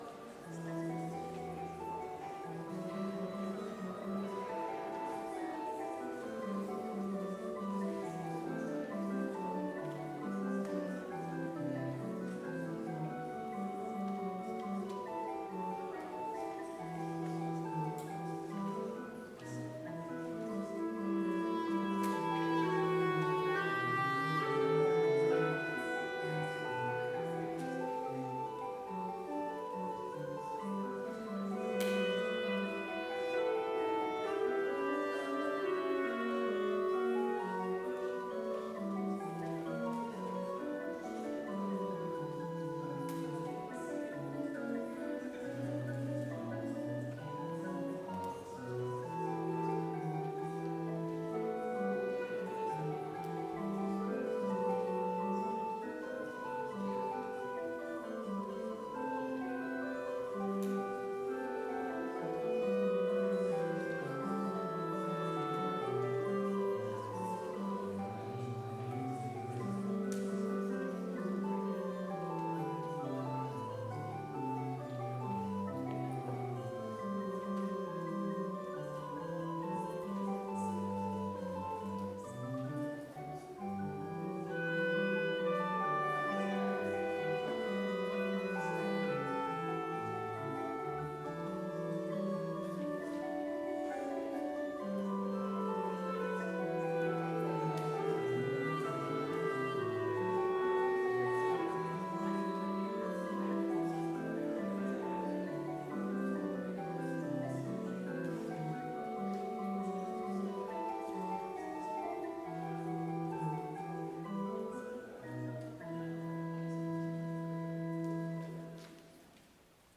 Vespers worship service
BLC Trinity Chapel, Mankato, Minnesota
Prelude
Choral Psalmody
Homily
choral setting